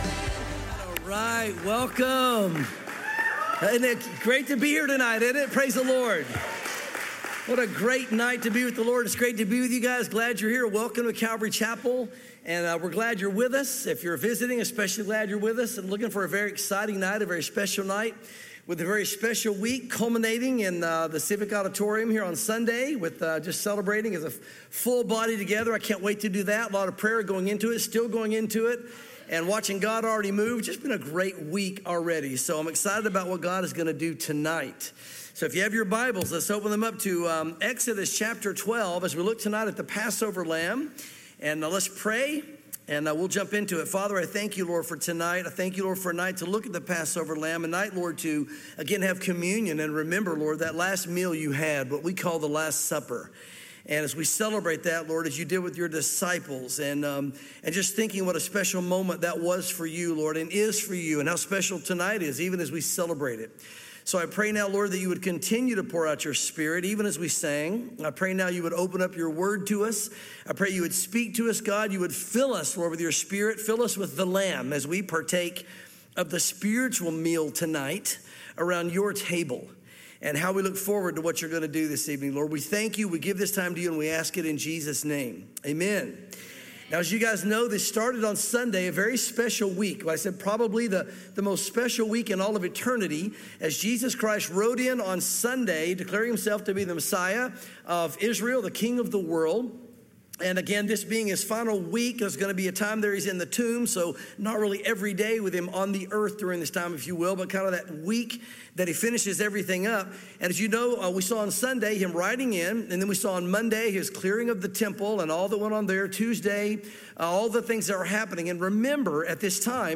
Calvary Knoxville Passover Service